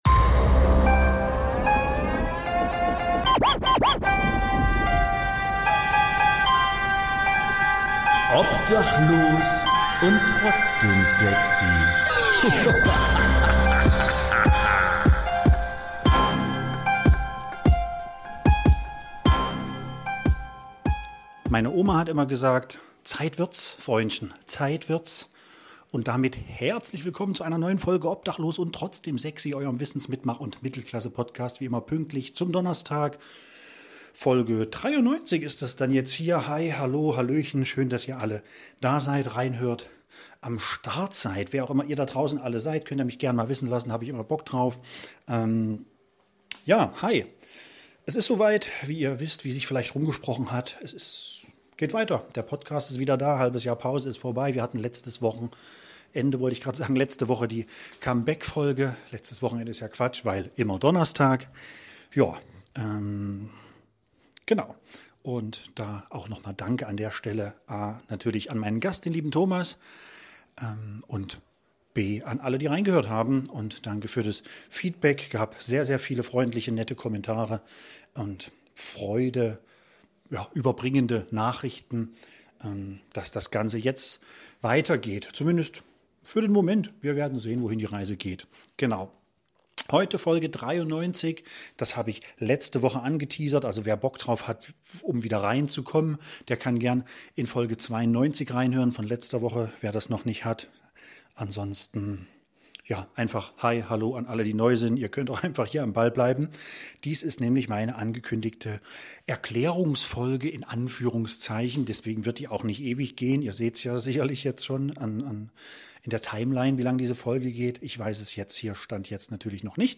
Heute zu Gast: Niemand!